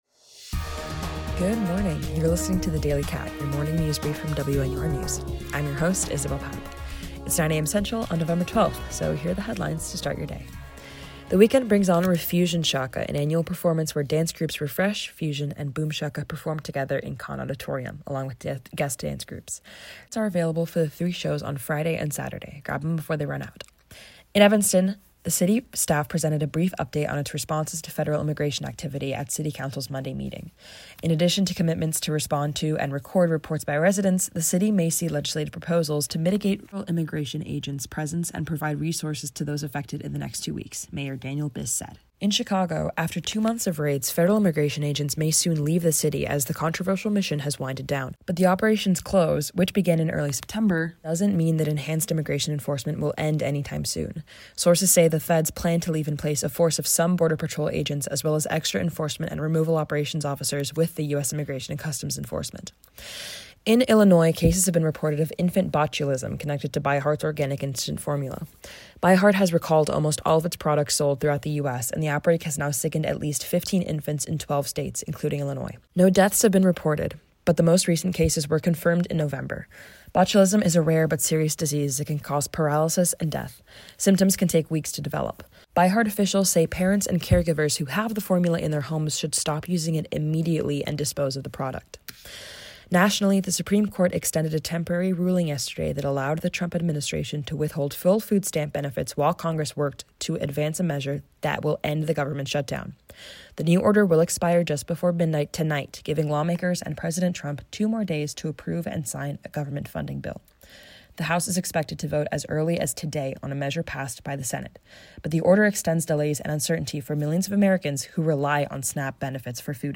WNUR News’ Daily Briefing – November 12, 2025 Refusionshaka, ICE updates in Evanston and Chicago, baby formula recalled and a terror attack in Pakistan WNUR News broadcasts live at 6 pm CST on Mondays, Wednesdays, and Fridays on WNUR 89.3 FM.